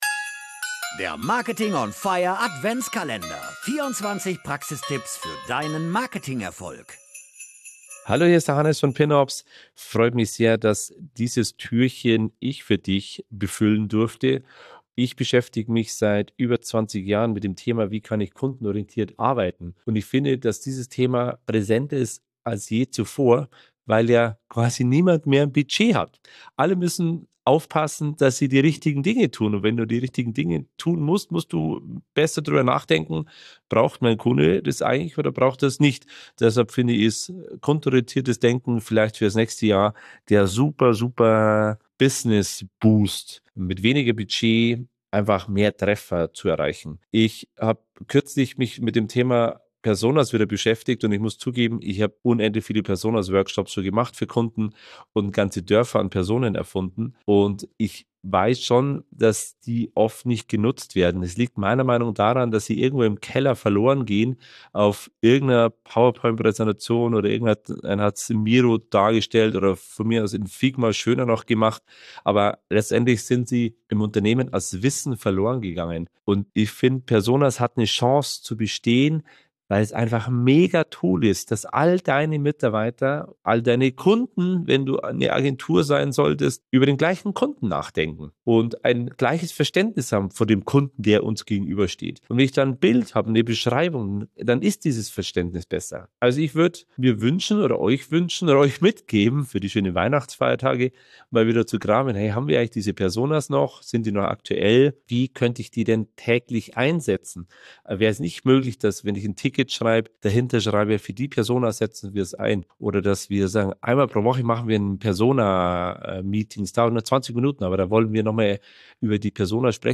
Sie liefern Einblicke in Marketing-Abteilungen, teilen strategische Tipps und nehmen Dich mit in den Marketing-Maschinenraum wo sie verraten, wie sie konkrete Maßnahmen umsetzen.